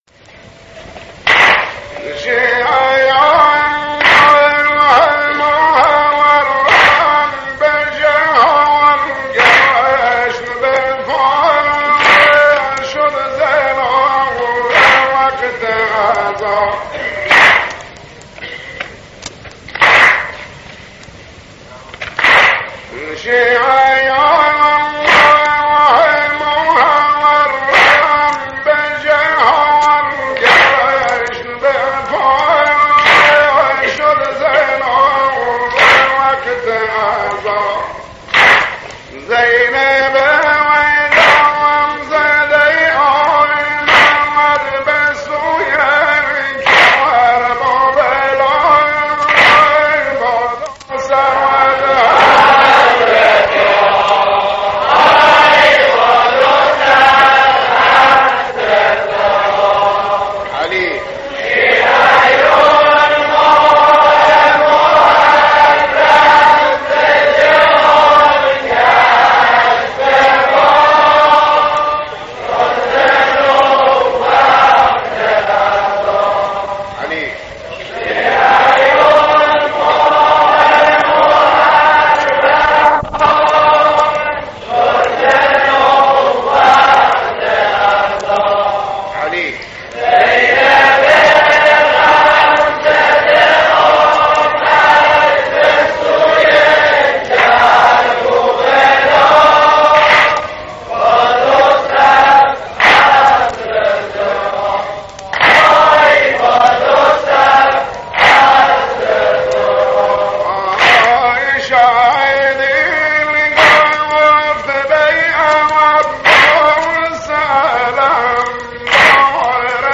مداحان بوشهری مداحان بوشهری